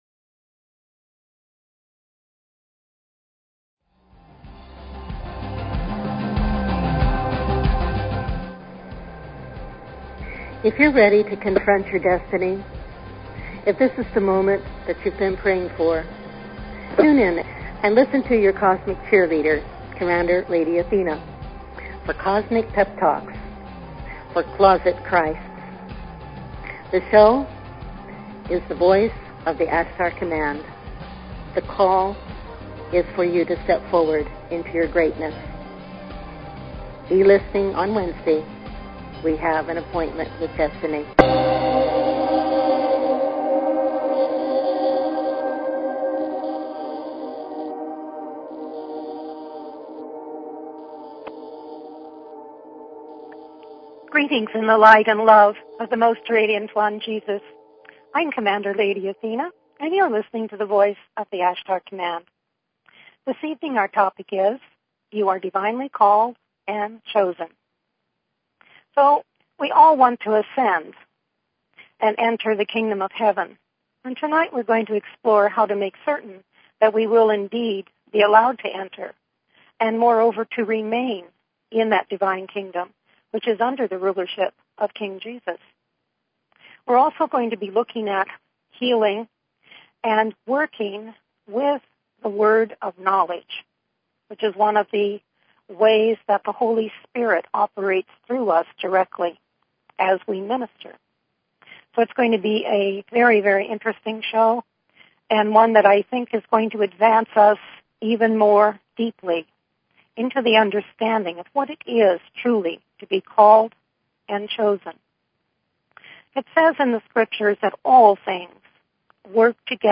Talk Show Episode, Audio Podcast, The_Voice_of_the_Ashtar_Command and Courtesy of BBS Radio on , show guests , about , categorized as
Many stories are shared plus a healing transmission & Word of Knowledge for those listening to this powerful seminar.